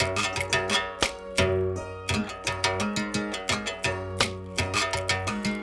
32 Berimbau 08.wav